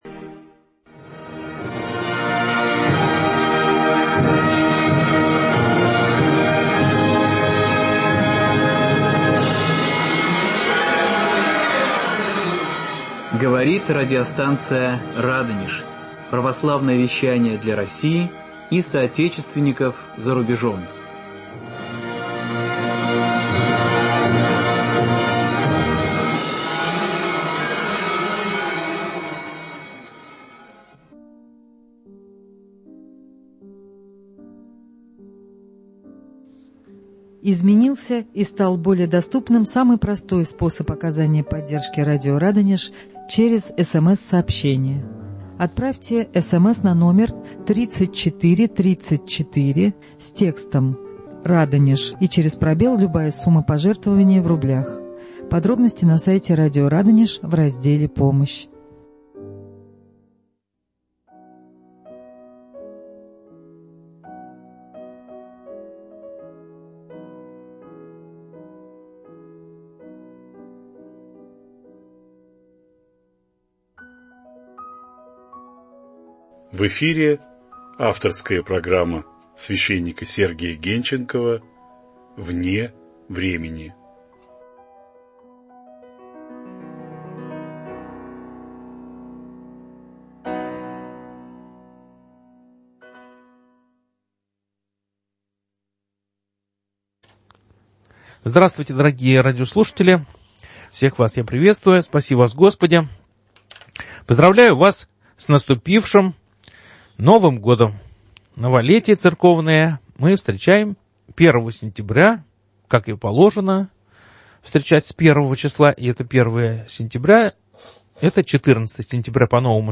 В прямом эфире радиостанции "Радонеж" новый выпуск программы "Вне времени".